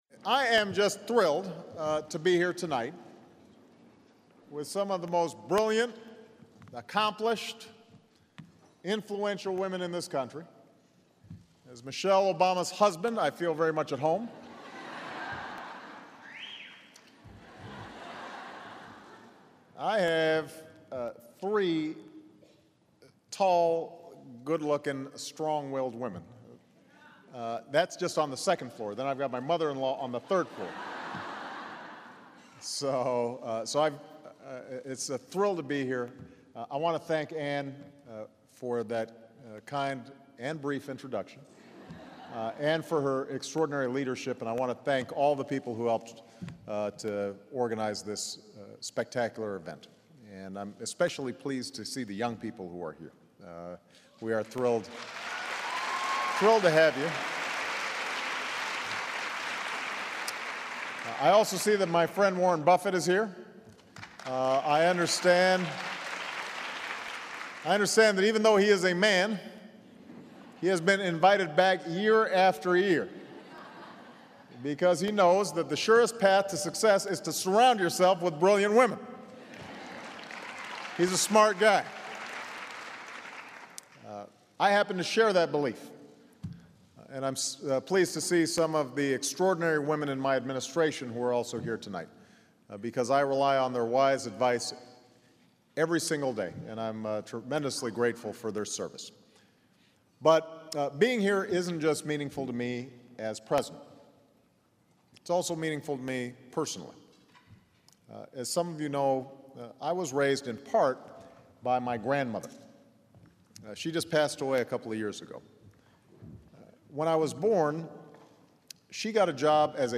U.S. President Barack Obama speaks at the 2010 Fortune Most Powerful Women Summit